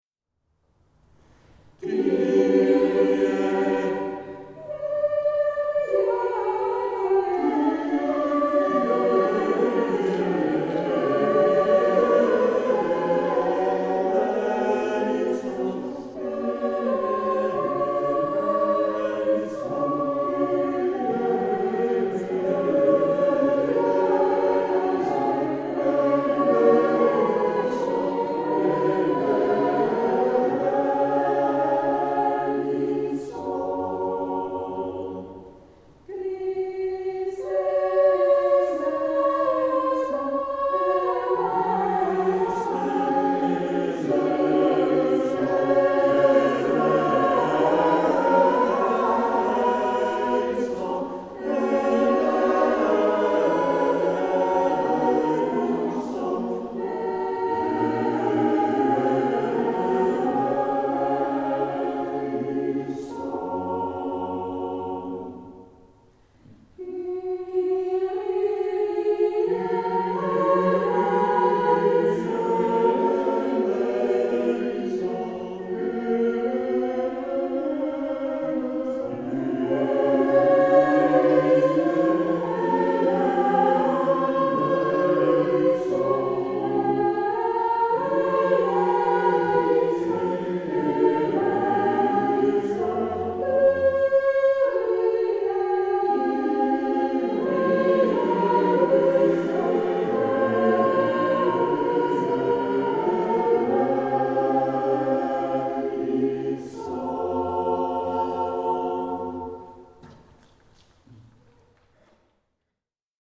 De eucharistieviering 's morgens